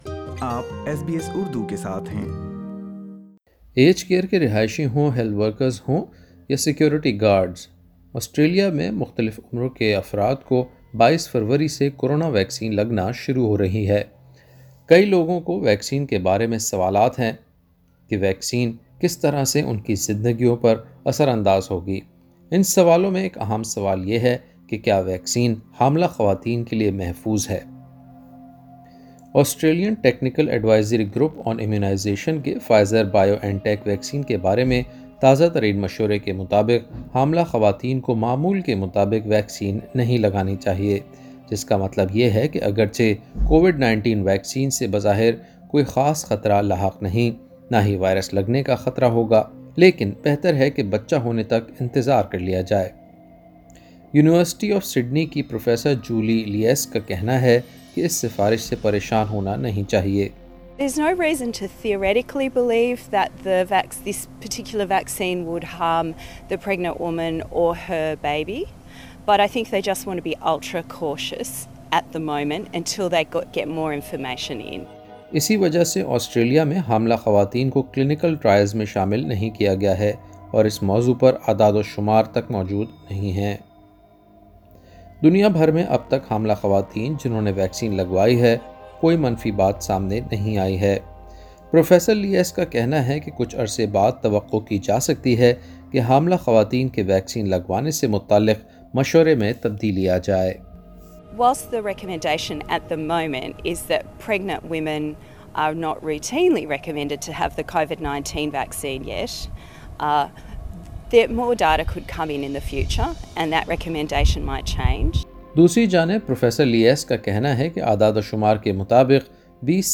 اِن اہم سوالوں کے جوابات سنِئے اس رپورٹ میں۔